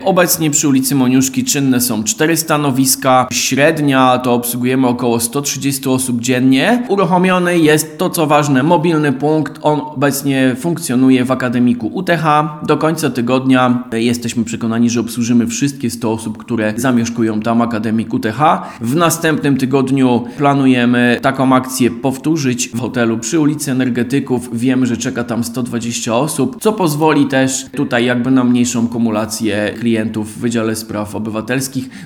Dokumenty można składać Urzędzie Miasta przy ul. Moniuszki 9, informuje Sekretarz miasta Michał Michalski: